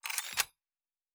Weapon 10 Reload 2.wav